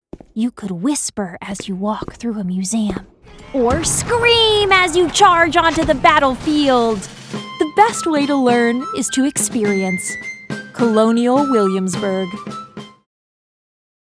hell, fein, zart, sehr variabel
Jung (18-30)
Commercial (Werbung)